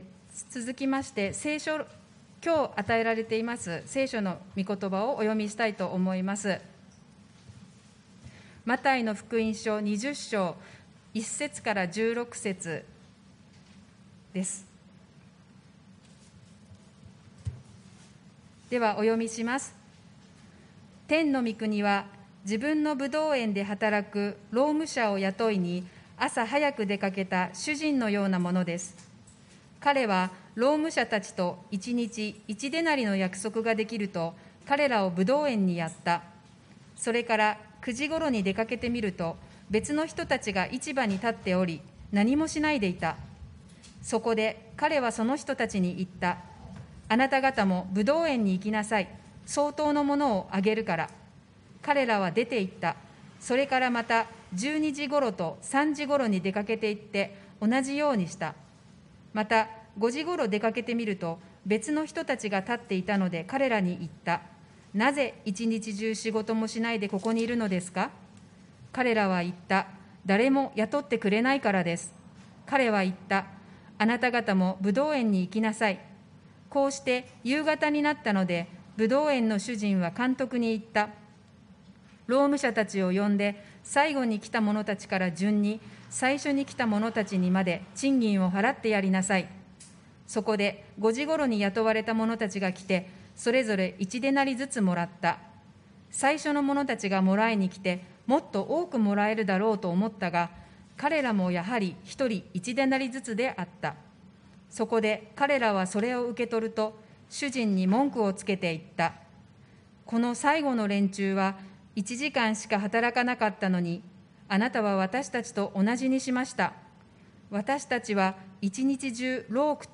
礼拝メッセージ(説教)